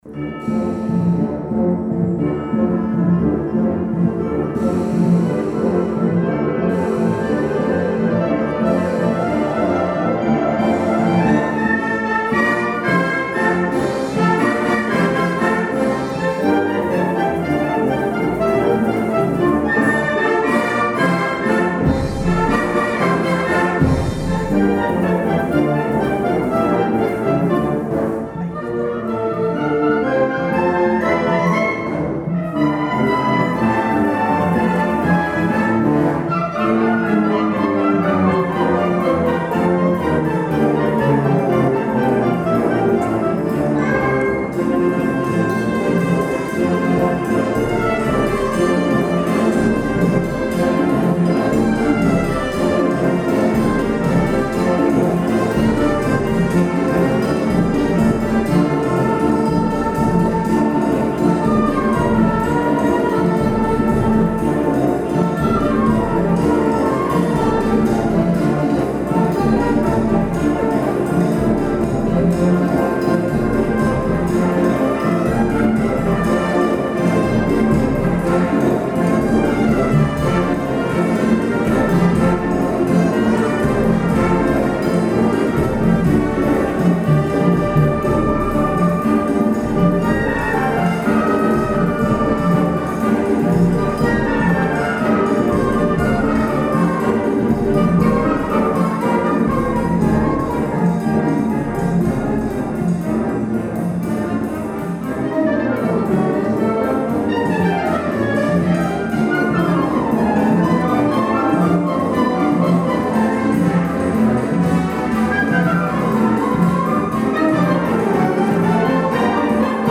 juntament amb Banda de Música de Llucmajor